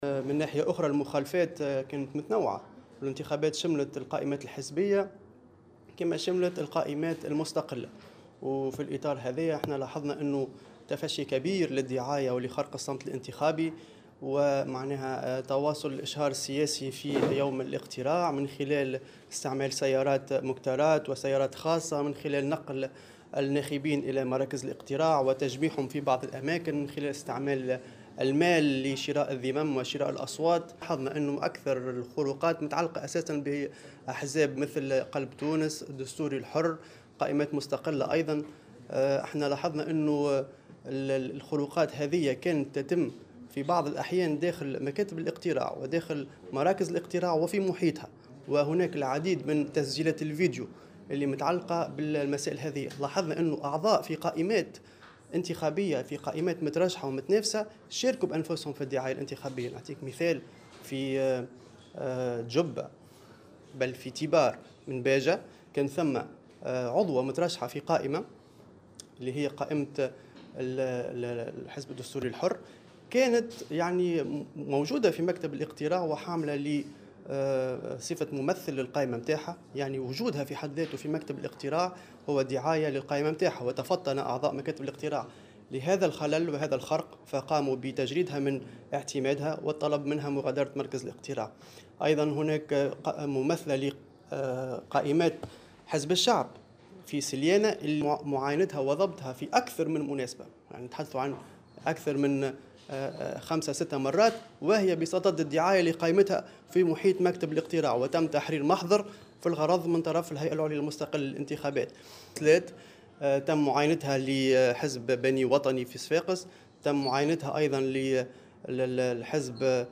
خلال ندوة صحفية انعقدت اليوم لتقديم التقرير الأولي المتعلق بملاحظة الانتخابات التشريعية